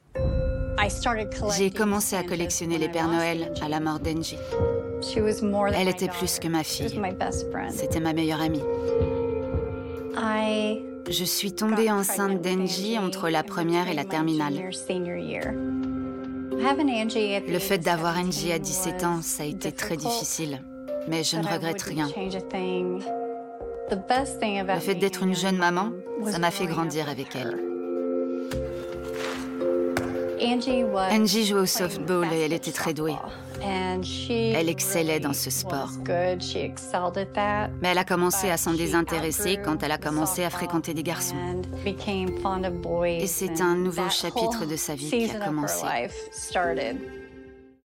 VOICE-OVER (TV réalité) – adulte - medium - présentatrice - sérieux/drame